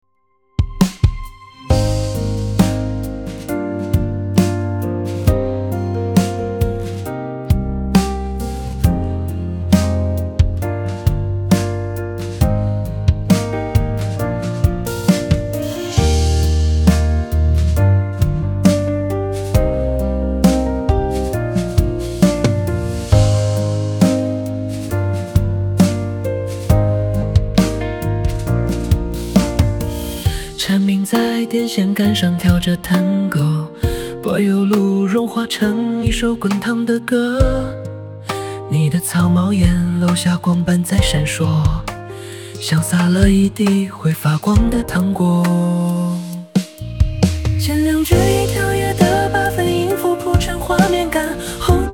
（建议节奏：轻快民谣流行，BPM 112，Key：D大调）
前两句以跳跃的八分音符铺陈画面感，后两
人工智能生成式歌曲